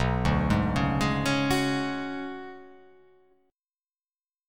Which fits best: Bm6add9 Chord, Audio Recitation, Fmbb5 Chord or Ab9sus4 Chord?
Bm6add9 Chord